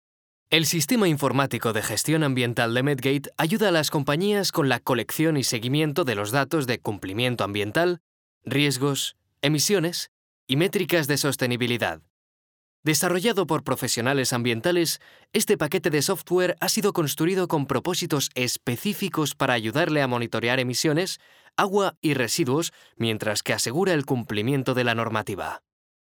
Young professional Spanish voice talent specialised in voice acting and film dubbing. Extremely versatile voice.
kastilisch
Sprechprobe: eLearning (Muttersprache):